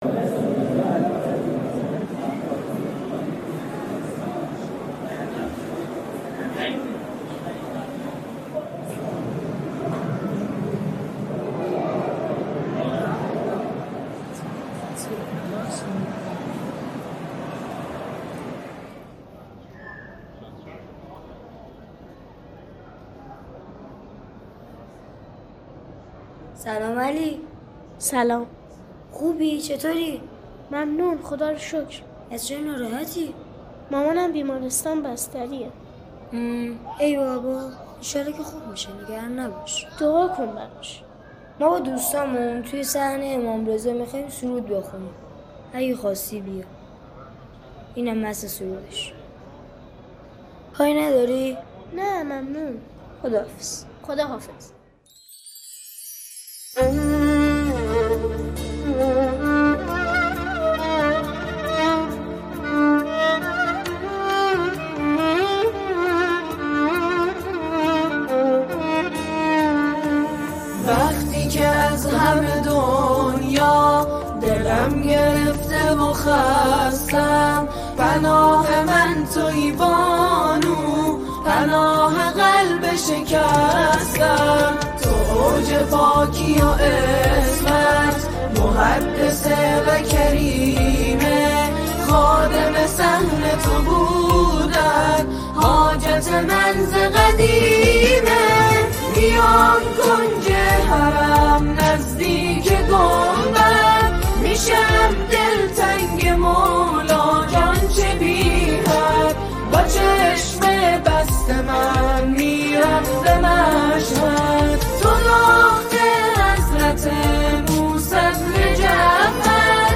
به مناسبت وفات حضرت فاطمه معصومه سلام الله علیها